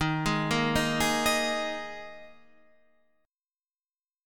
D#sus4 chord